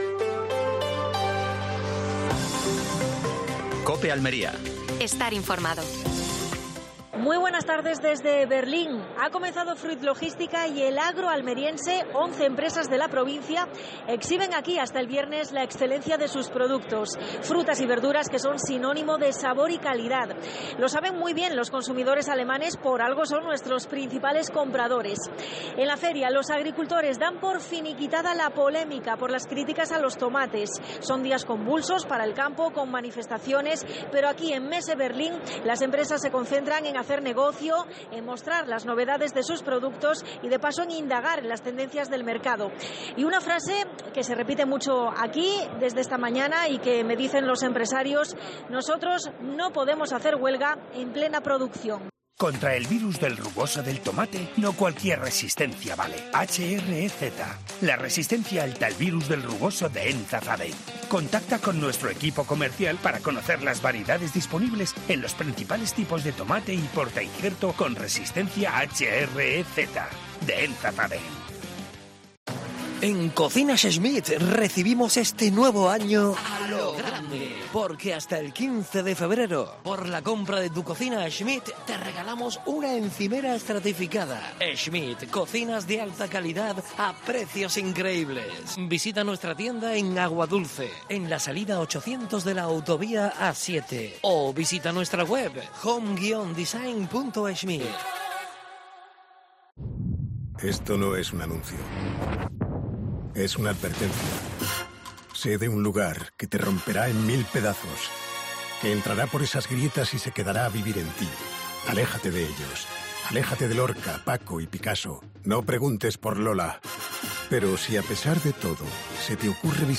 AUDIO: Última hora en Almería. Fruit Logística desde Berlín. Entrevista a María del Mar Vázquez (alcaldesa de Almería).